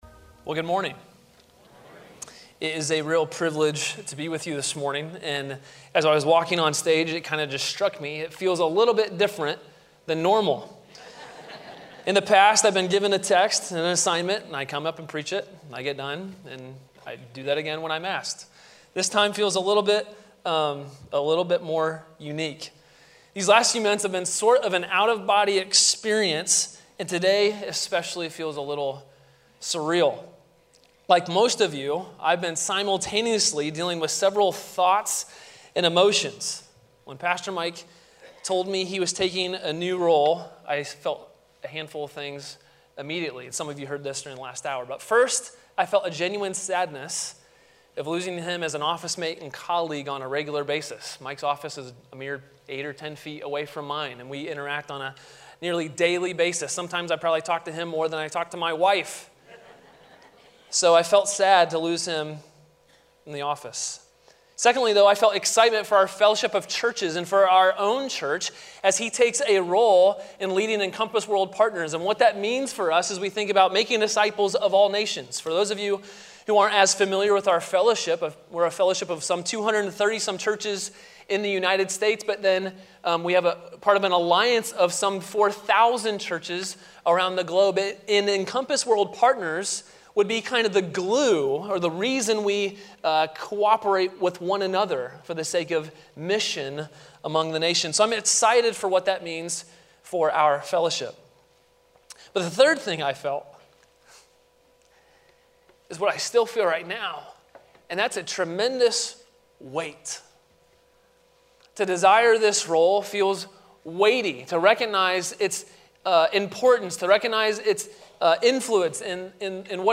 Sermon: "All Glory be to Christ!" from Guest Speaker • Grace Polaris Church